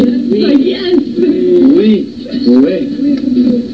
Americans speaking french